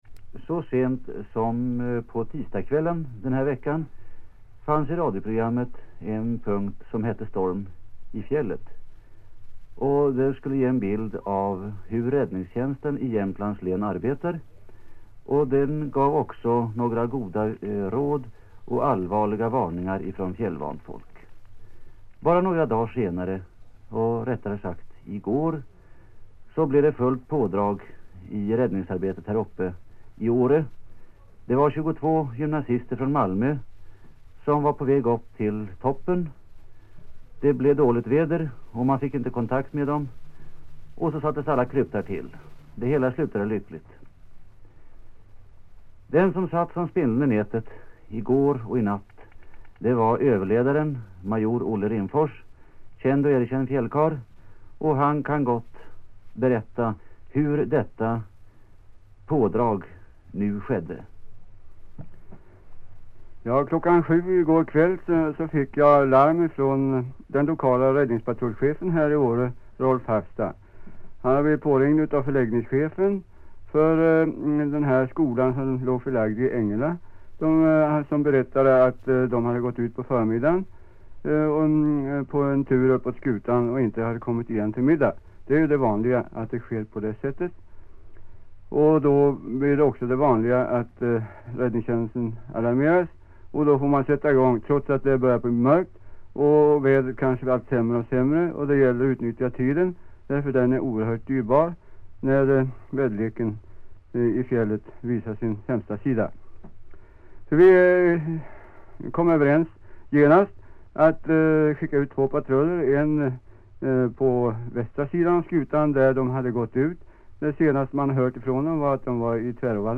Dagens Eko: intervju